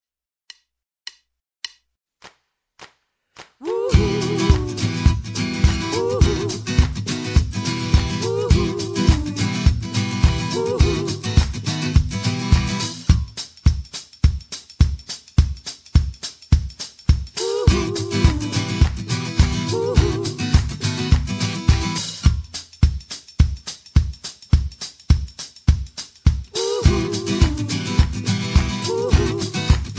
Tags: country , hip hop , top 40 , backing tracks